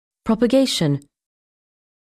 듣기 시작 영 [prɔ̀p-]